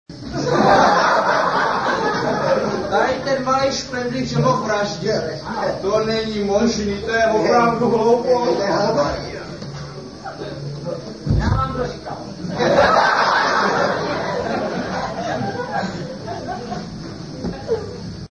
Vystoupení se konalo 29. června 1999.
Za jeden večer jsme zvládli dvě představení.